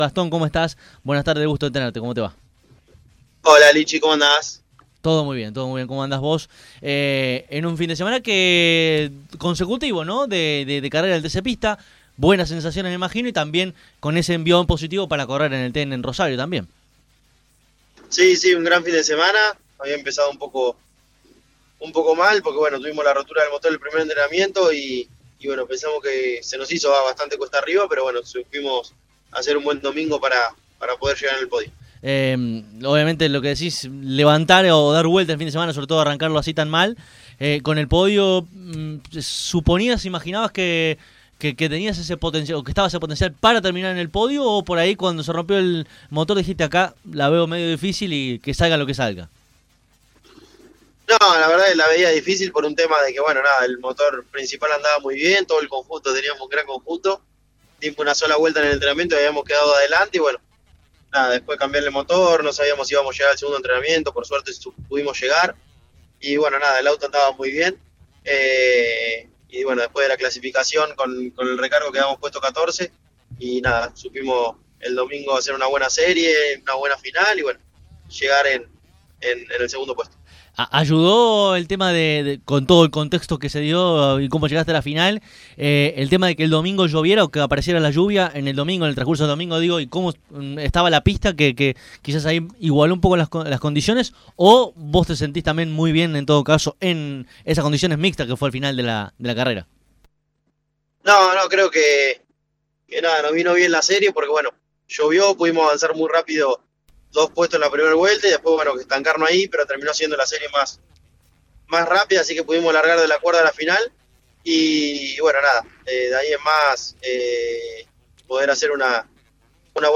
El piloto bonaerense pasó, este martes, por los micrófonos de nuestro programa y reflexionó sobre múltiples cuestiones en una interesante charla.